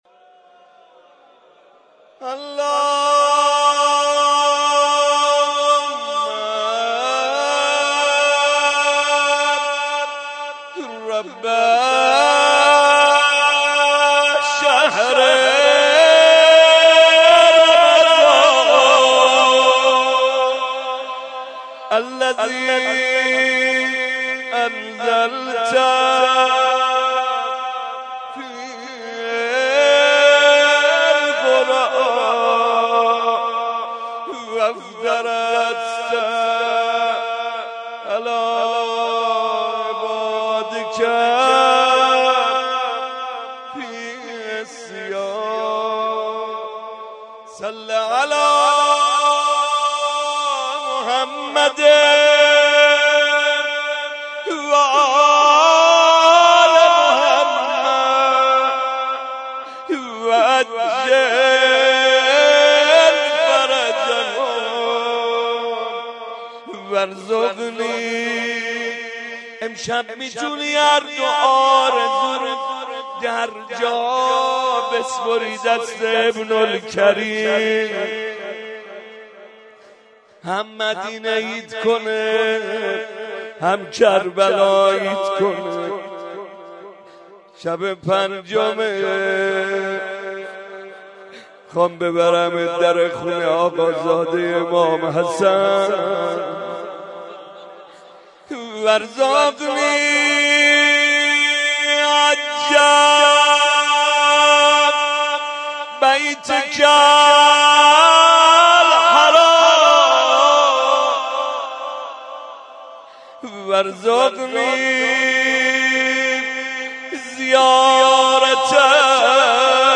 مناجات
02.monajat.mp3